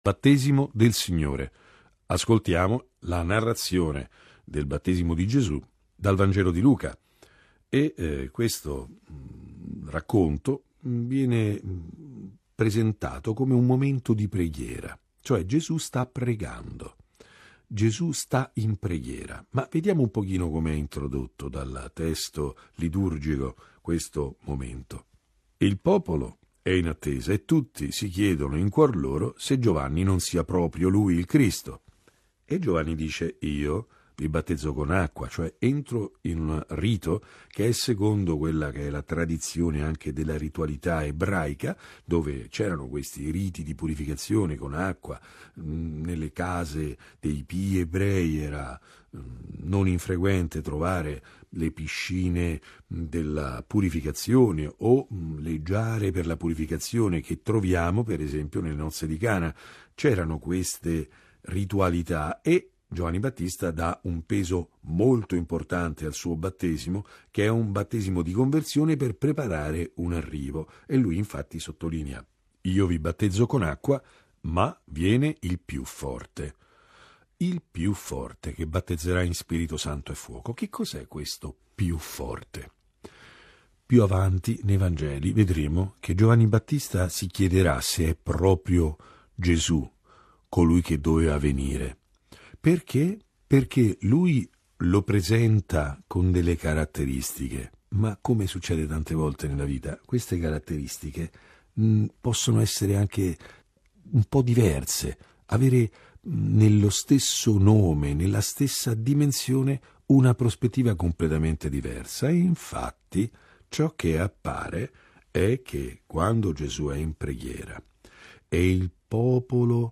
Battesimo del Signore - Commento al Vangelo